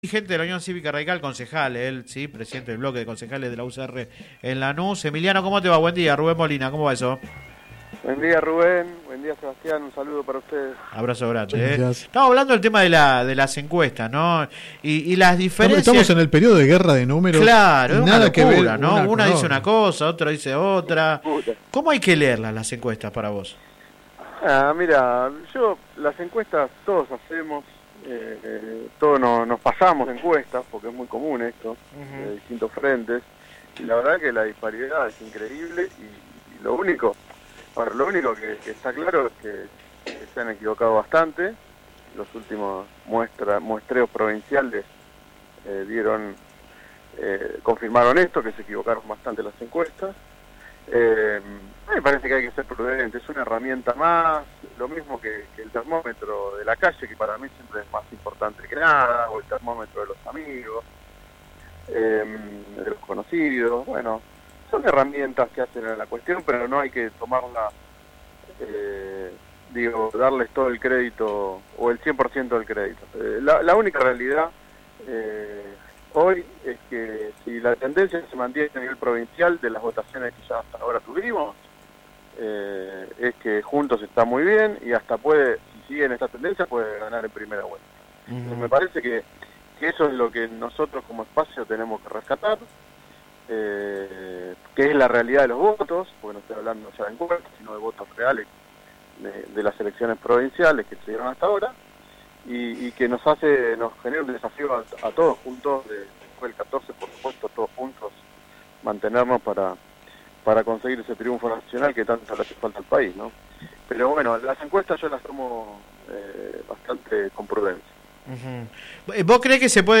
En diálogo con el programa radial Sin Retorno (lunes a viernes de 10 a 13 por GPS El Camino FM 90 .7 y AM 1260) no descartó un triunfo de Juntos en primera vuelta. Al mismo tiempo manifestó un fuerte respaldo a la precandidatura a intendente de Diego Kravetz.